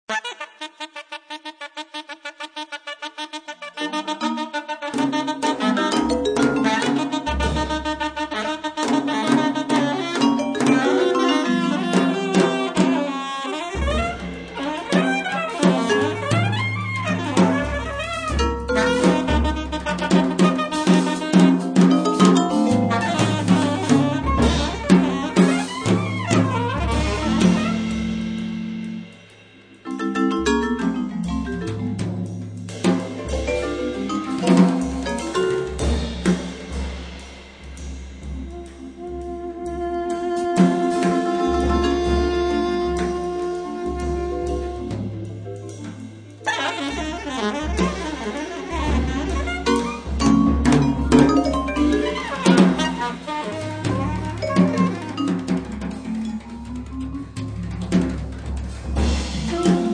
vibrafono e percussioni
sax soprano e tenore
batteria